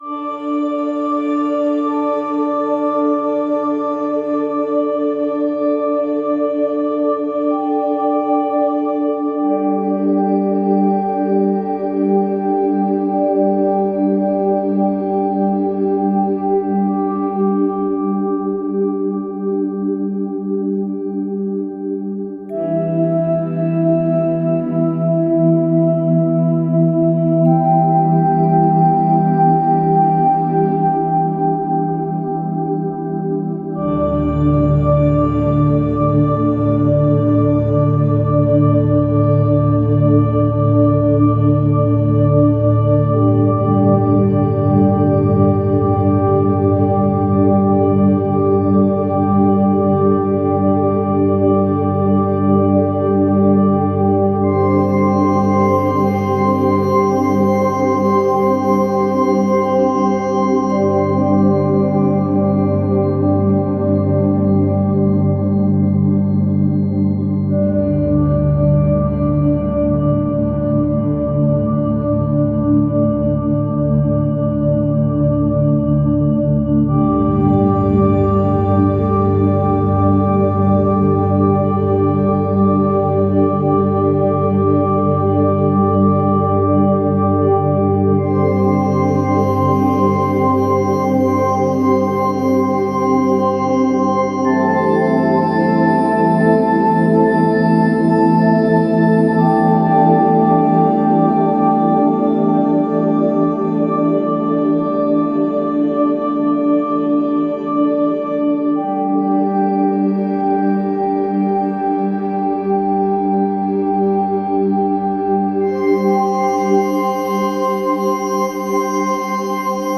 meadow1.opus